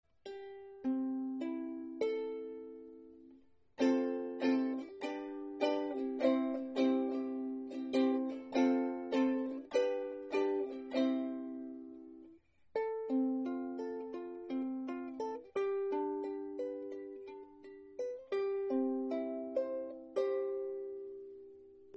Meine Soundbeispiele sind alle am gleichen Tag mit einem Zoom H2 aufgenommen.
Gespielt habe ich nur ein paar Akkorde.
Brüko Sopran mit Aquila Saiten
Kopus Riegel-Nussbaum, spiegelverleimt, massiv
Hals Riegel-Ahorn, Griffbrett Nussbaum